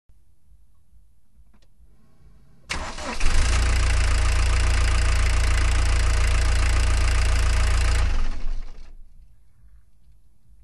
I wanted to record how an engine sounds in a Golf.